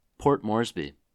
Port Moresby (/ˈmɔːrzbi/
En-us-Port_Moresby.oga.mp3